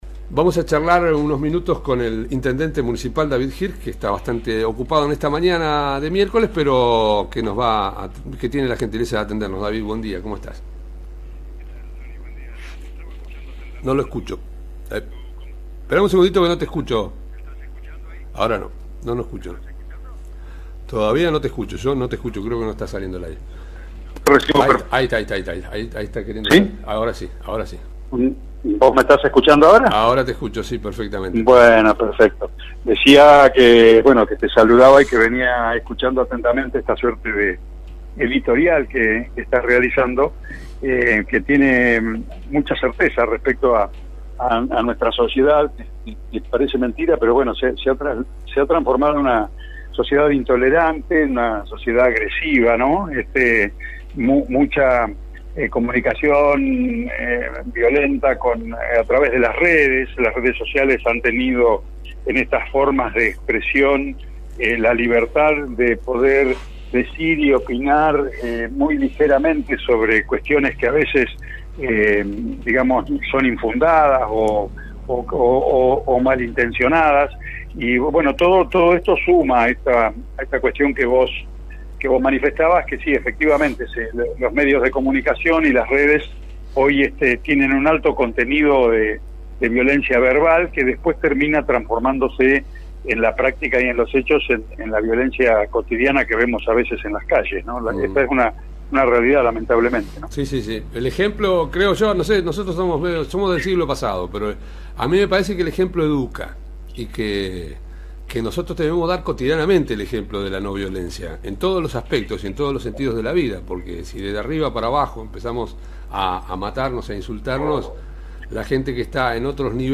El jefe comunal de Adolfo Alsina conversó en exclusiva con nuestro medio para explicar las razones que llevaron a levantar parte del piedraplen en el sector del camino al Haras y que tuvo críticas en las redes sociales. Además contó los preparativos para celebrar los 142 años de la fundación de nuestra ciudad.